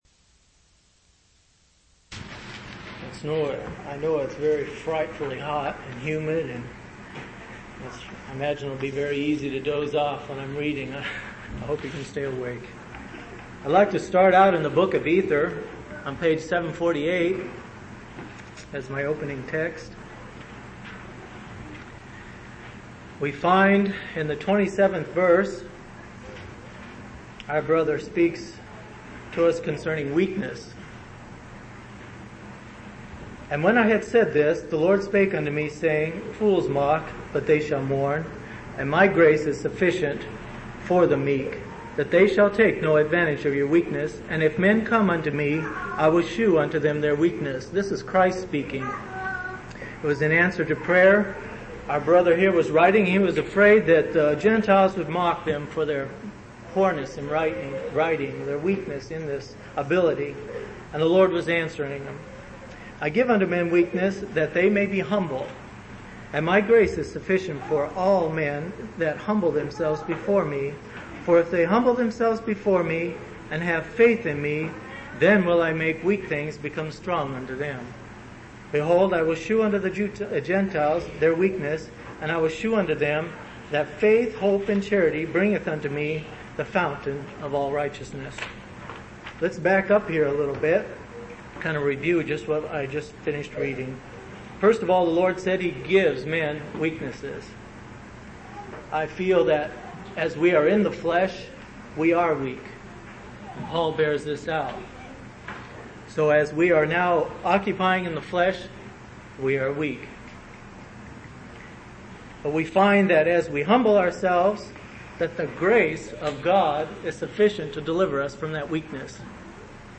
7/10/1983 Location: Phoenix Local Event